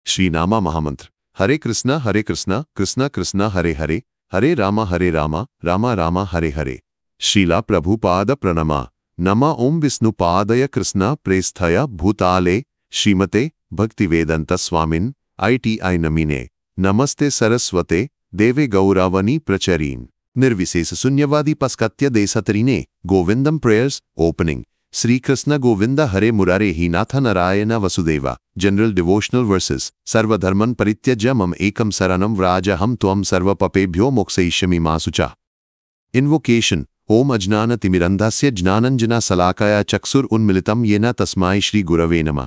ChatGPT Diacritic Indian English TTS.mp3